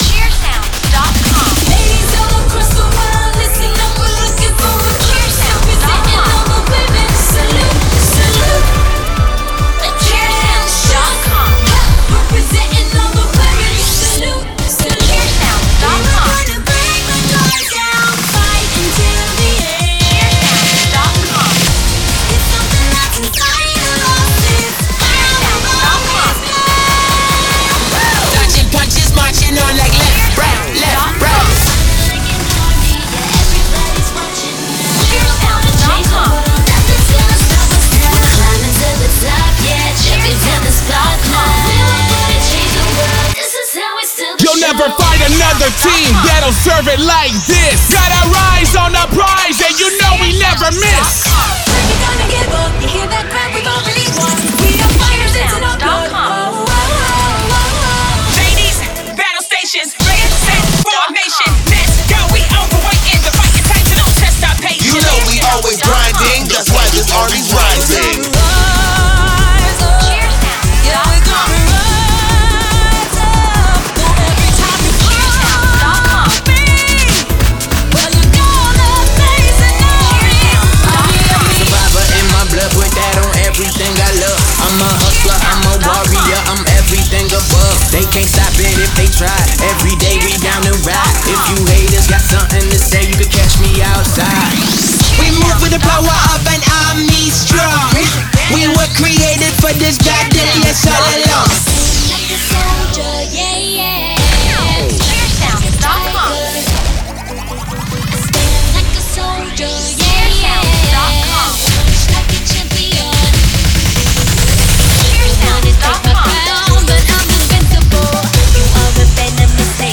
Premade Cheer Music Mix